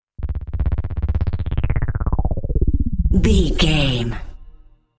Sound Effects
Atonal
magical
mystical